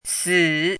chinese-voice - 汉字语音库
si3.mp3